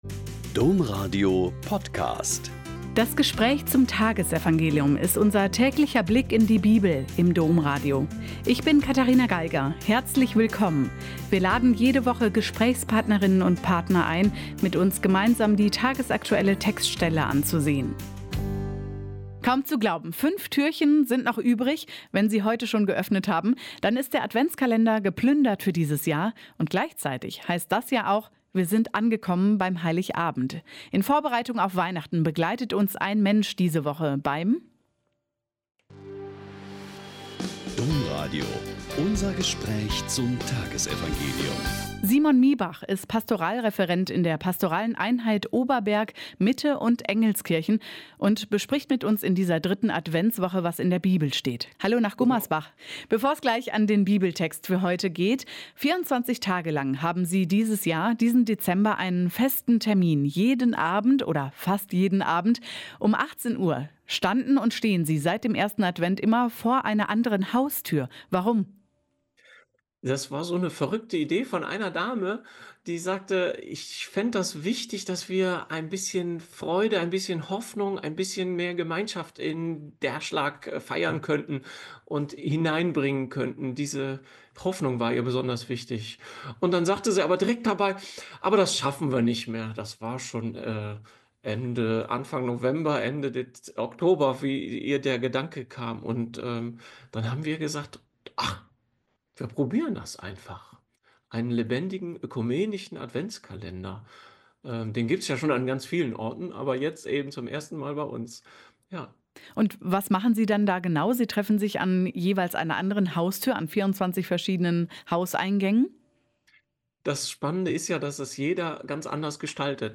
Lk 1,5-25 - Gespräch